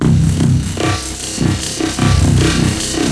.wav 16 bit 44khz, Microsoft ADPCM compressed, mono,
Lmamen.wav Timestretched amen breakbeat 35k